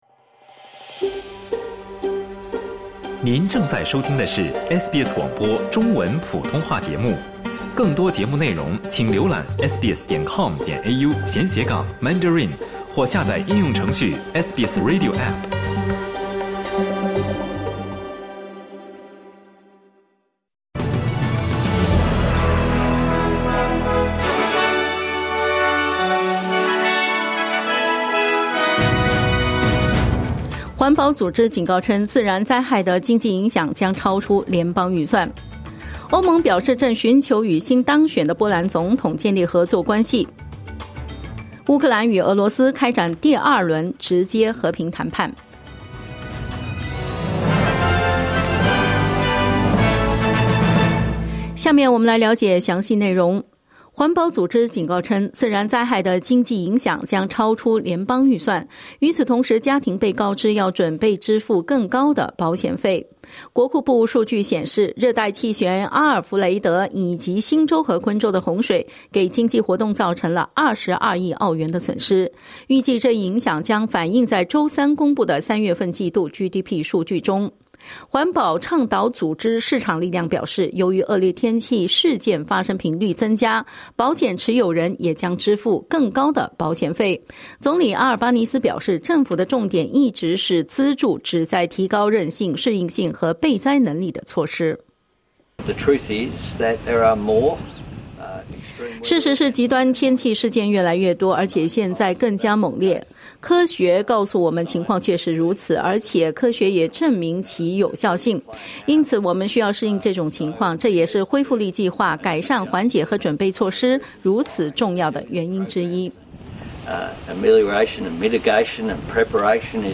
SBS早新闻（2025年6月3日）